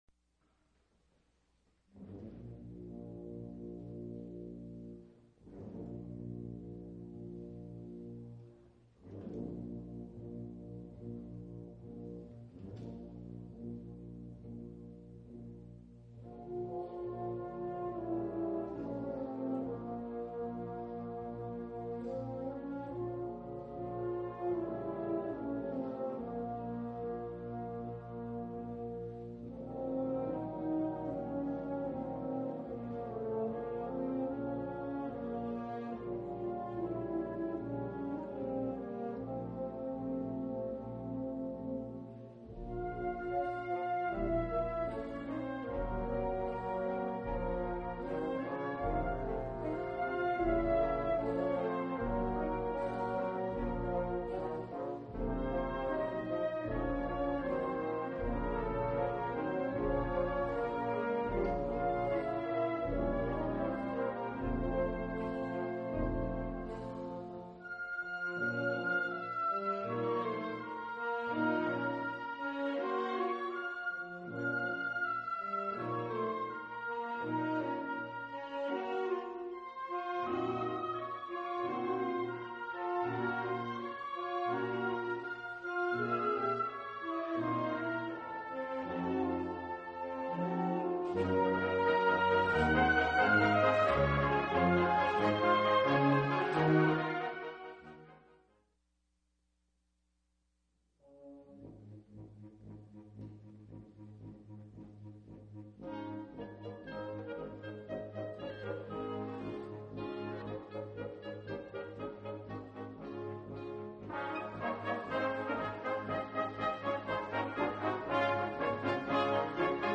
Key: B-flat minor (original key)
Begining and middle, 2'40"